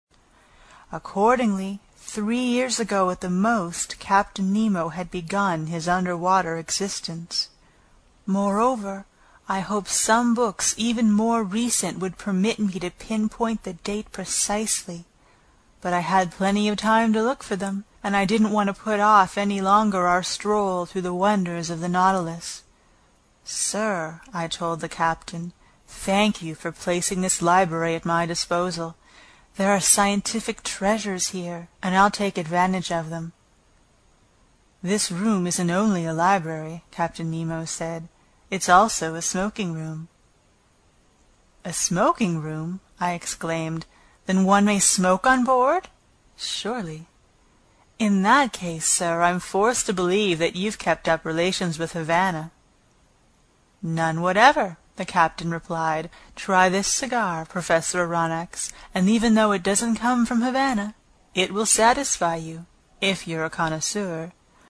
英语听书《海底两万里》第154期 第11章 诺第留斯号(5) 听力文件下载—在线英语听力室